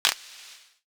Sizzle Click 8.wav